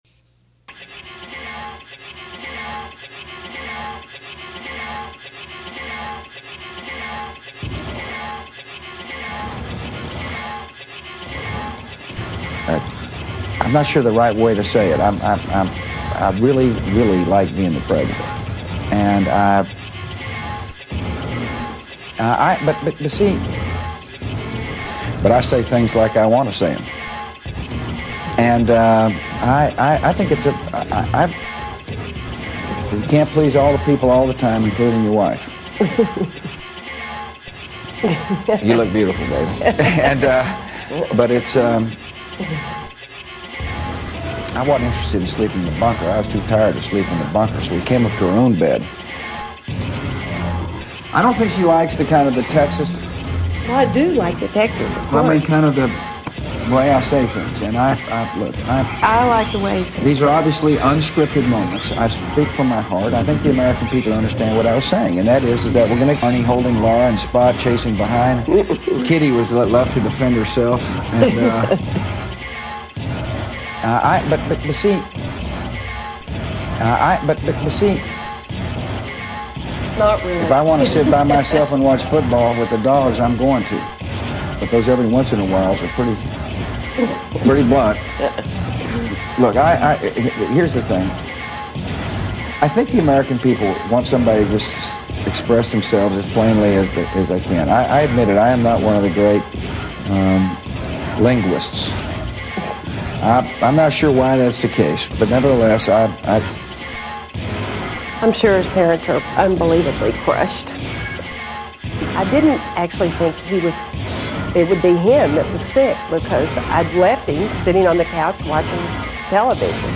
Creator: Big City Orchestra
Diced dialogue between George W. and Laura Bush.